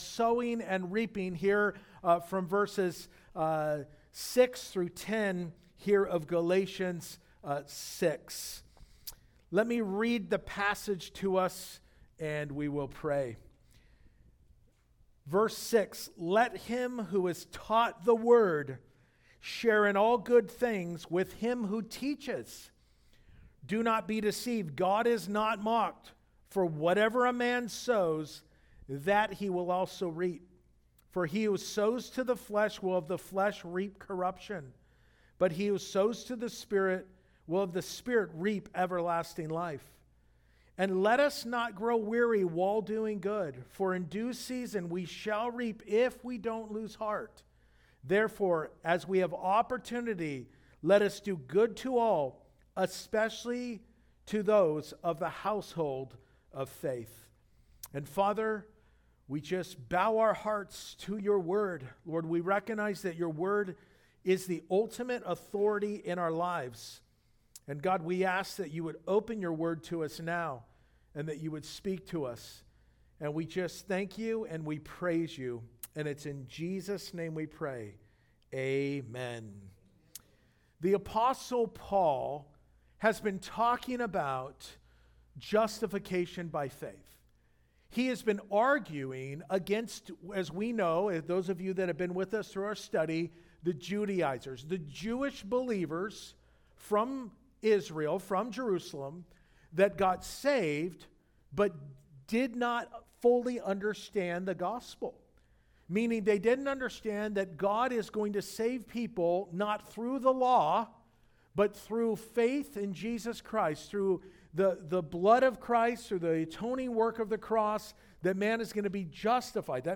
Principles+of+Sowing+and+Reaping+2nd+Service.mp3